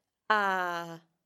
There is also the long à, represented by the “grave” accent ( ` ).